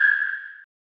sonar5.mp3